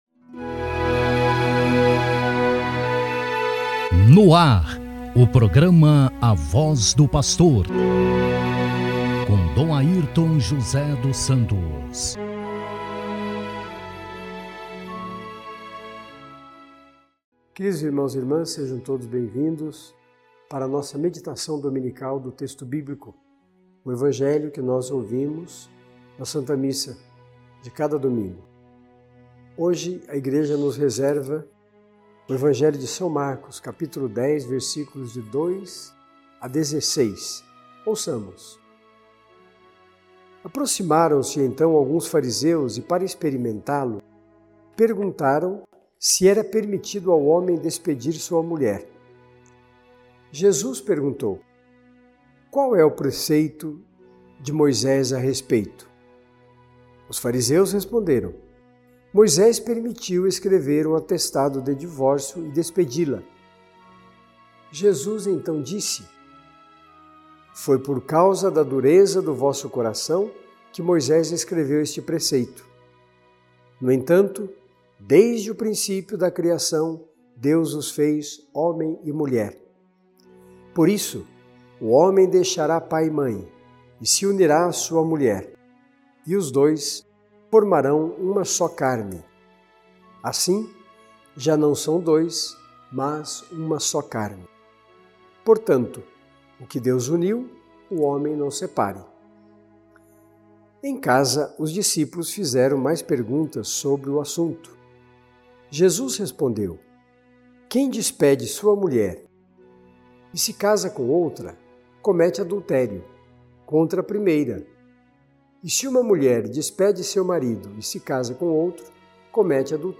SAUDAÇÃO DE DOM GERALDO LYRIO ROCHA AO PAPA FRANCISCO I